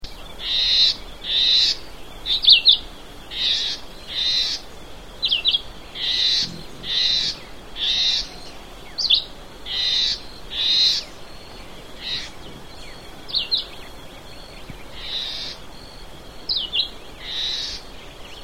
Most likely call to be heard is a nasal, querulous “tshay” or “chway”, although migrants are usually silent.
Calls include a nasal whining “quee”.
(Red-eyed Vireo subspecies chivi – from xeno-canto.)
Persistent song, sung all day, a variable series of deliberate, short phrases.
157-red-eyed-vireo-ssp-chivi-alarm-call-and-song-xeno-canto.mp3